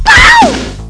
moonwalker_scream.wav